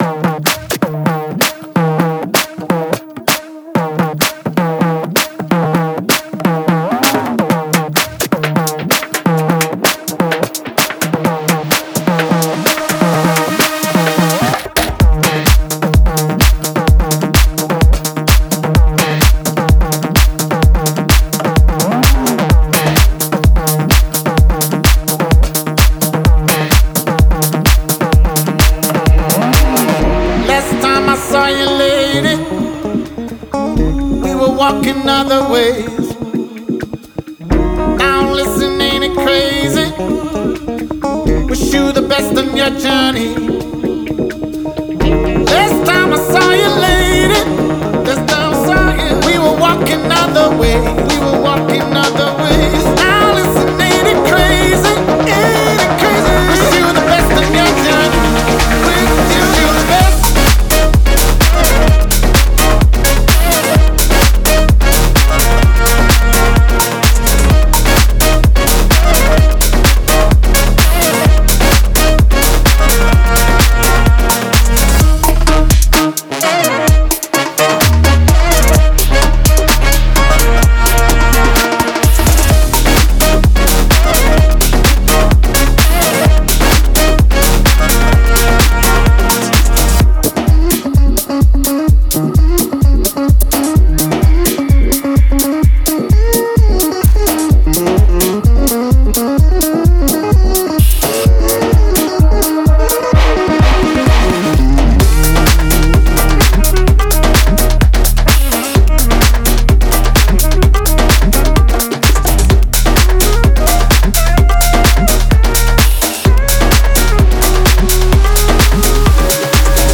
Future House, Happy, Epic, Euphoric, Energetic, Dreamy, Dark